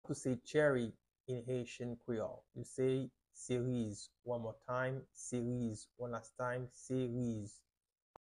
How to say “Cherry” in Haitian Creole – “Seriz” pronunciation by a native Haitian Teacher
“Seriz” Pronunciation in Haitian Creole by a native Haitian can be heard in the audio here or in the video below:
How-to-say-Cherry-in-Haitian-Creole-–-Seriz-pronunciation-by-a-native-Haitian-Teacher.mp3